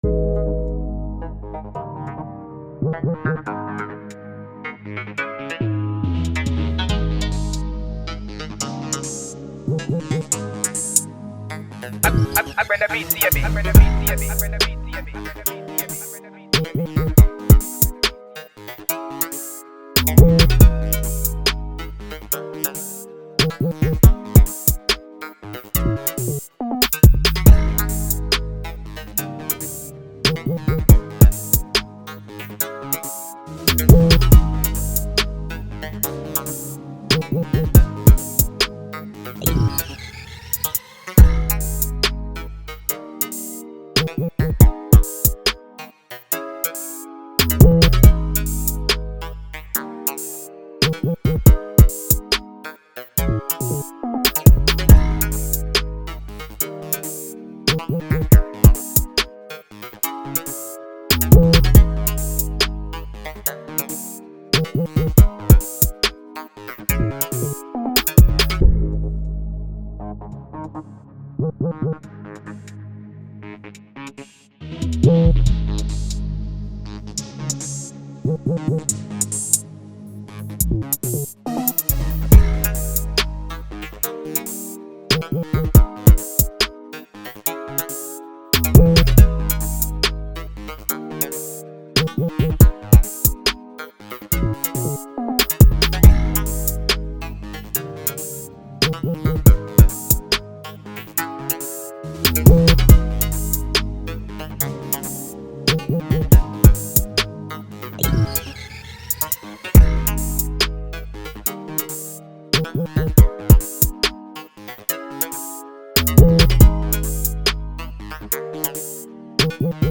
Ghana MusicInstrumentalsMusic
Off the Ep lies this free Drill Beat titled.